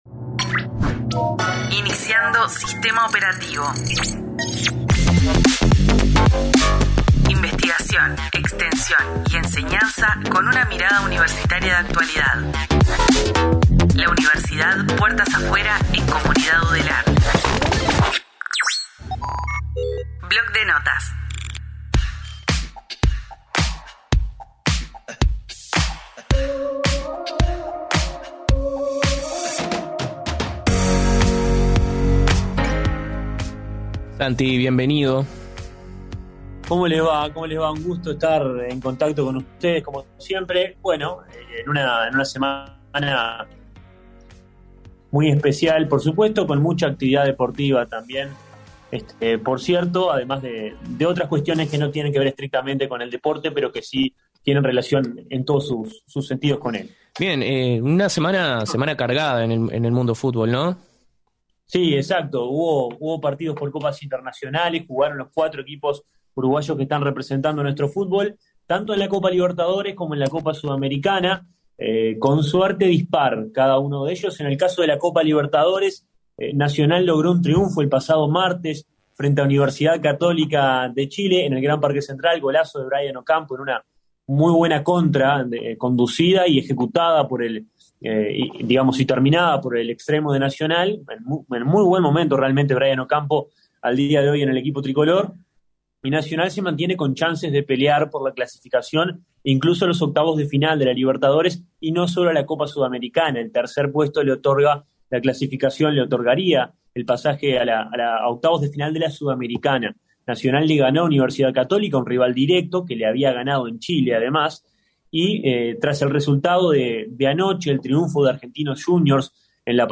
Comunidad Udelar, el periodístico de UNI Radio.